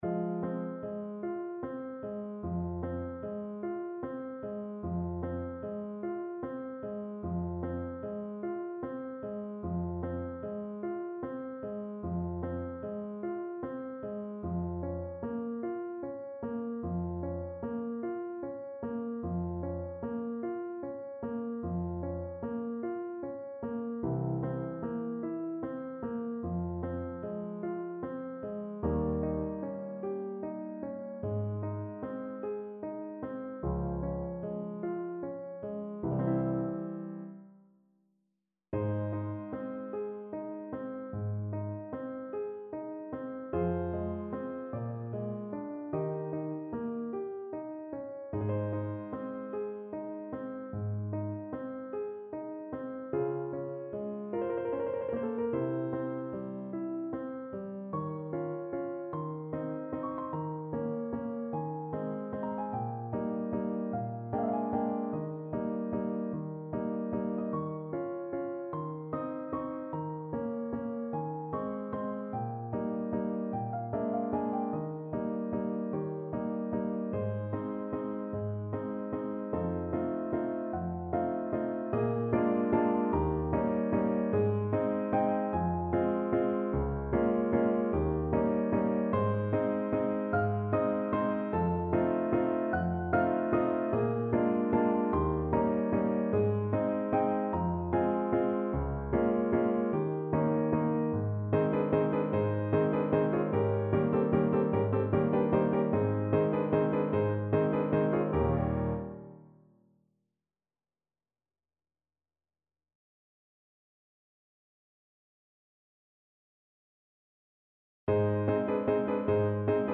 Free Sheet music for Soprano Voice
Play (or use space bar on your keyboard) Pause Music Playalong - Piano Accompaniment Playalong Band Accompaniment not yet available transpose reset tempo print settings full screen
Ab major (Sounding Pitch) (View more Ab major Music for Soprano Voice )
~ = 100 Adagio =50
4/4 (View more 4/4 Music)
Classical (View more Classical Soprano Voice Music)